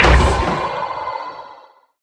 Minecraft Version Minecraft Version 25w18a Latest Release | Latest Snapshot 25w18a / assets / minecraft / sounds / mob / irongolem / death.ogg Compare With Compare With Latest Release | Latest Snapshot
death.ogg